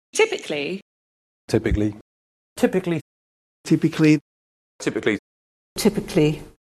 In pronunciation, however, these are all generally treated as if they were -icly. Here are native speakers (mainly British) saying basically, typically, historically and systematically: